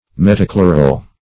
Search Result for " metachloral" : The Collaborative International Dictionary of English v.0.48: Metachloral \Met`a*chlo"ral\, n. [Pref. meta- + chloral.]